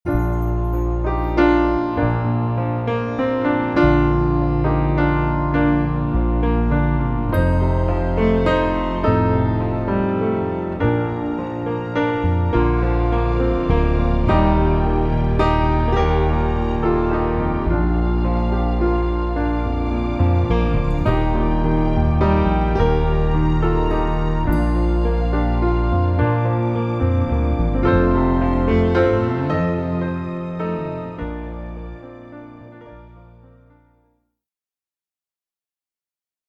ヒーリングＣＤ
優しいピアノの音がリラックス効果を高めます。